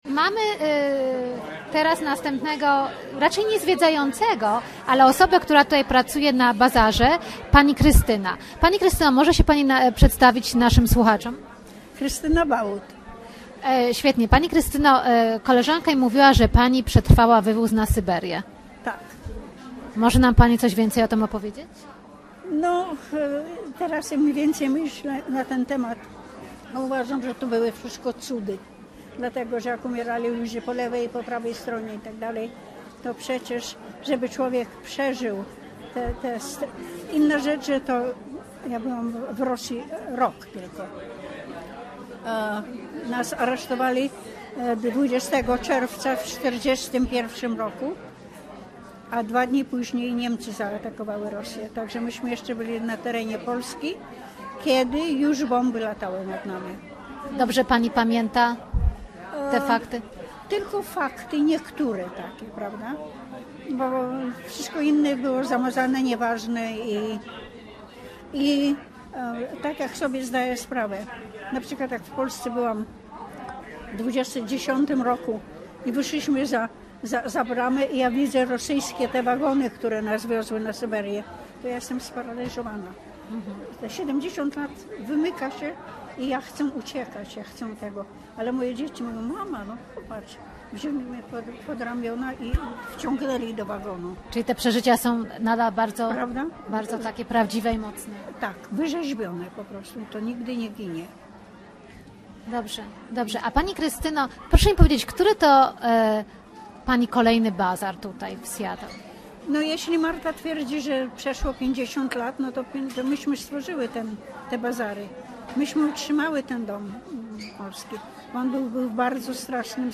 Zapraszamy do wysłuchania rozmów przeprowadzonych na wiosennym bazarze w Domu Polskim.